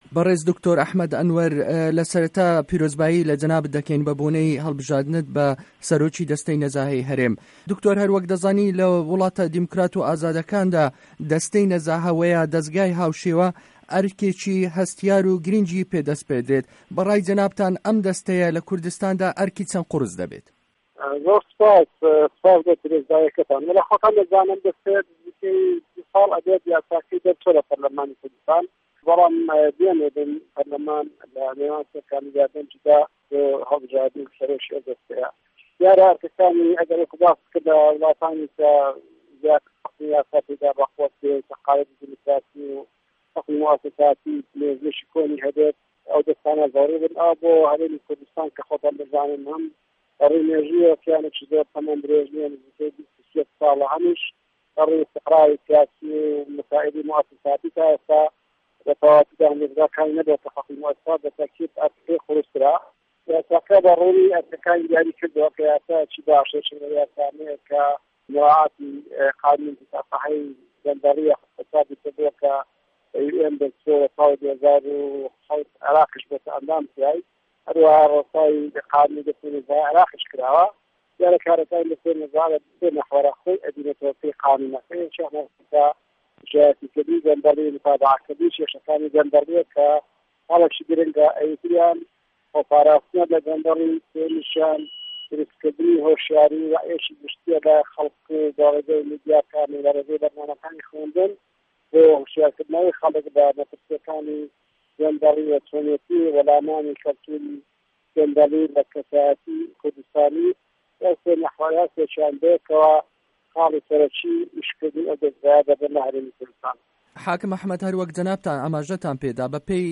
ده‌قی گفتوگۆی ده‌نگی ئه‌مه‌ریکا له‌گه‌ڵ حاکم ئه‌حمه‌د ئه‌نوه‌ر